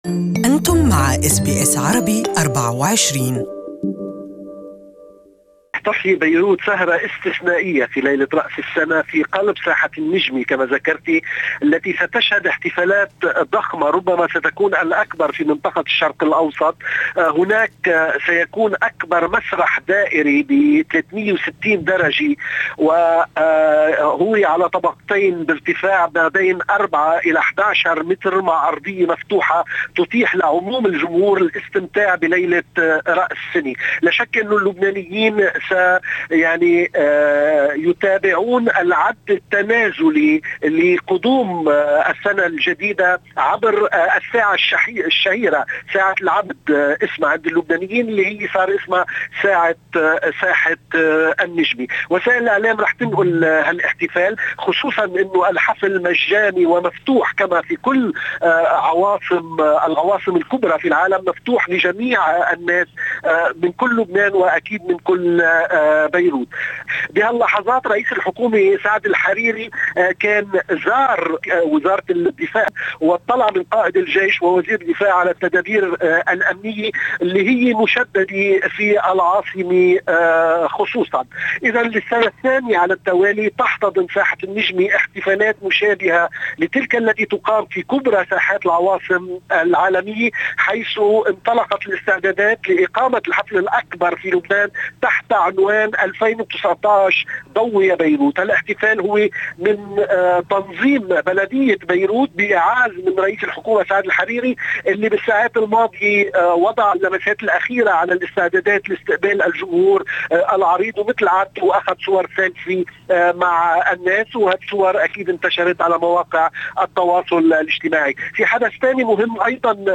Our correspondent in Beirut has the details